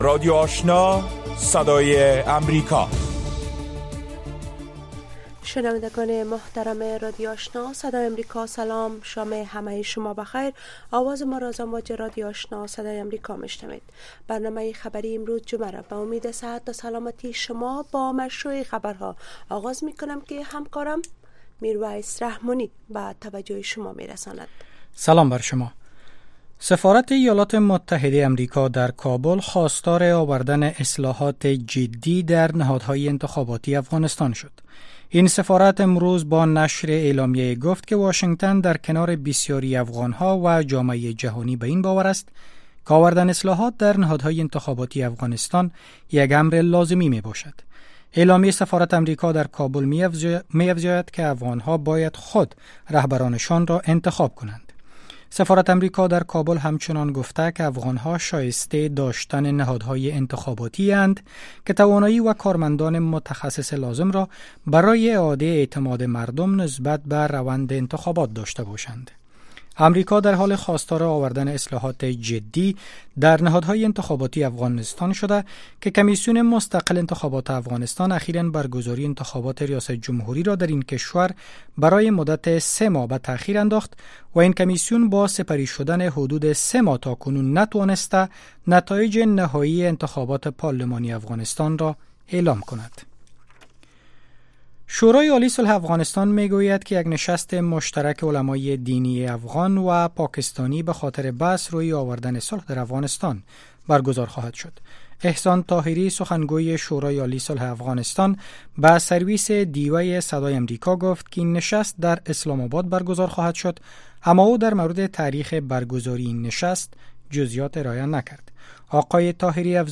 در نخستین برنامه خبری شب خبرهای تازه و گزارش های دقیق از سرتاسر افغانستان، منطقه و جهان فقط در سی دقیقه.